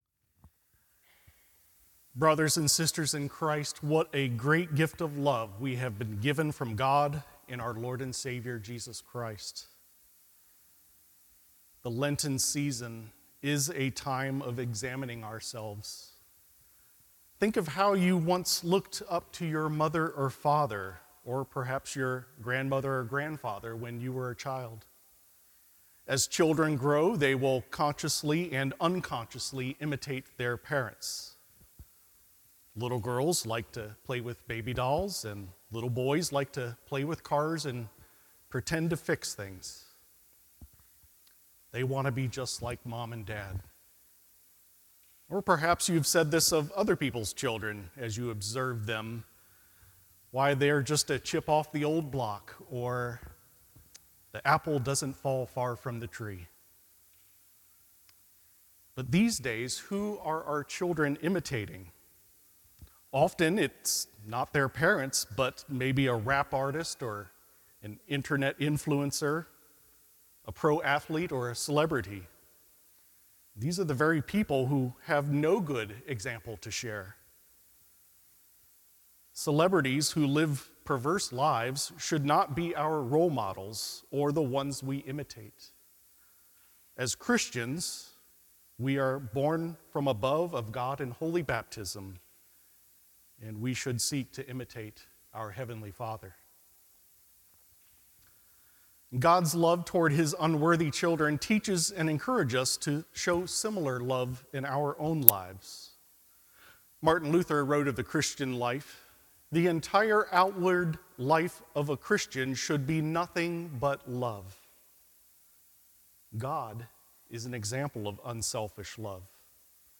Lent III – Midweek